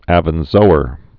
(ăvən-zōər) also Ibn Zuhr (ĭbən zr) Full name Abu Marwan Abd al-Malik Ibn Zuhr. 1091?-1162?